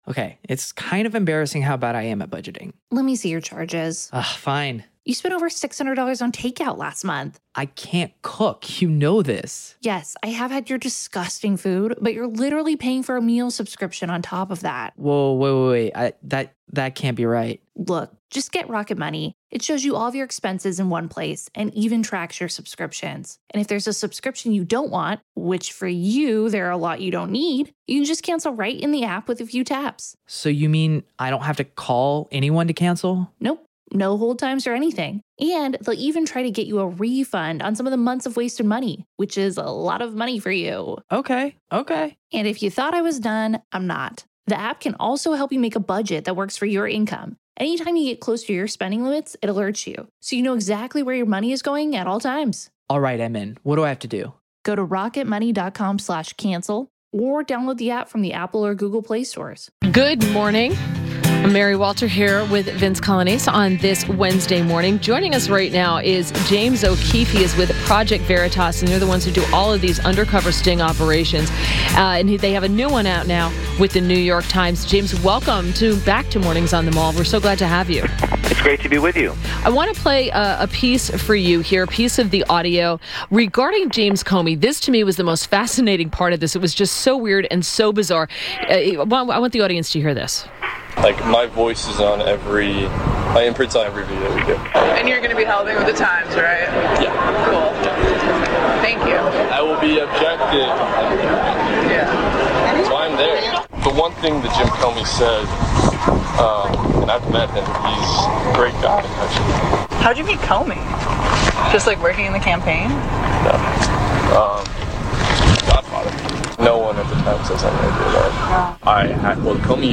WMAL Interview - JAMES O'KEEFE - 10.11.17